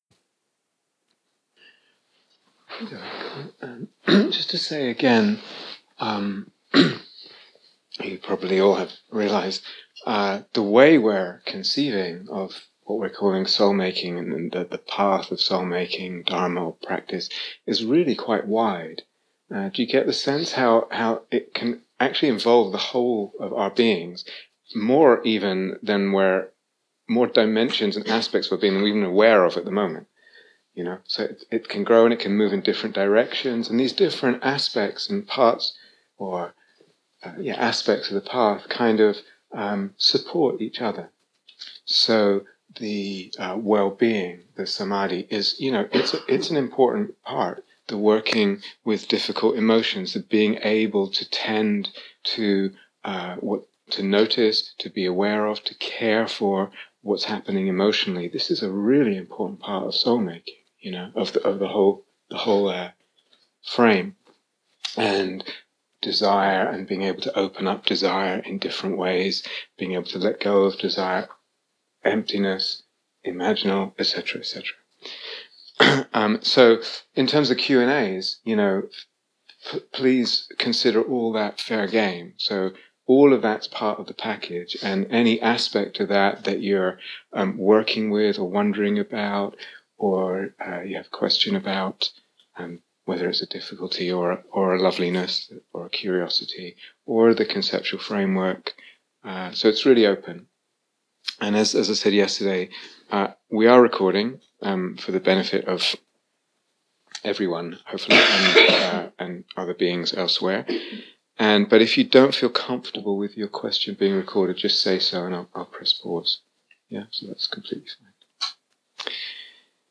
Trusting in Soulmaking (Q & A)